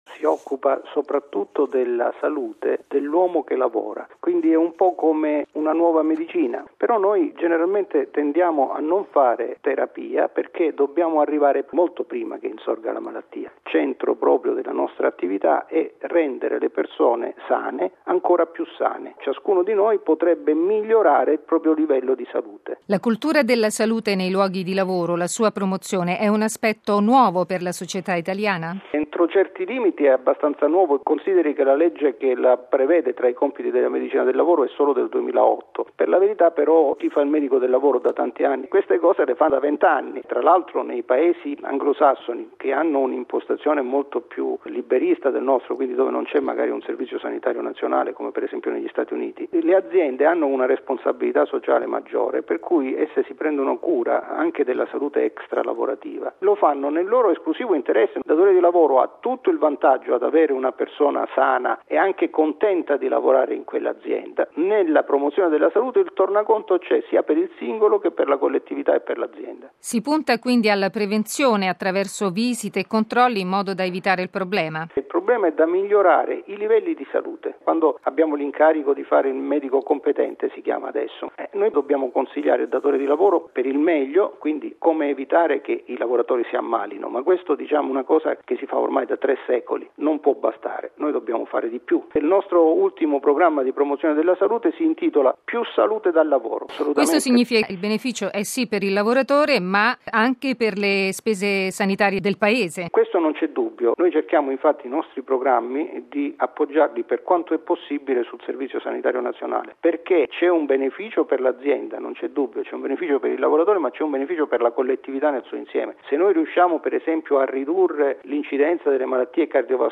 Radio Vaticana - Radiogiornale